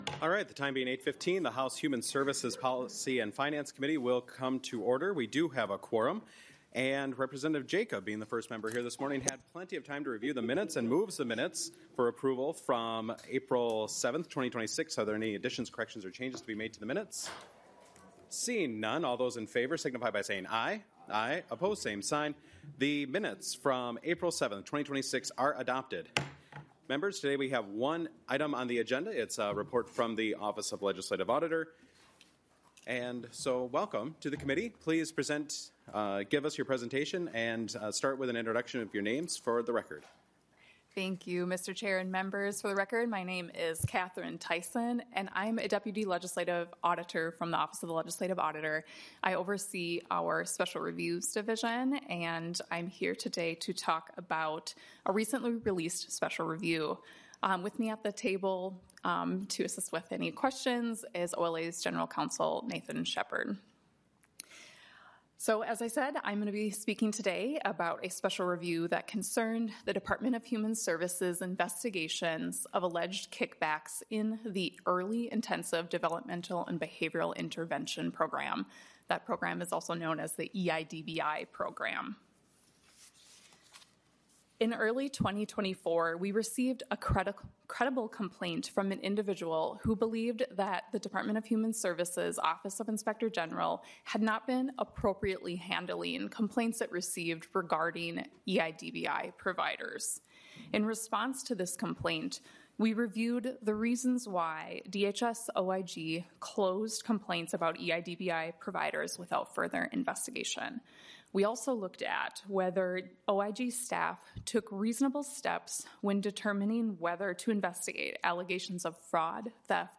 00:28 - Office of Legislative Auditor presentation: Department of Human Services Investigations of Alleged Kickbacks in the Early Intensive Developmental and Behavioral Intervention Program.
Show Full Schedule Agenda: *This is an informational hearing I. Call To Order II.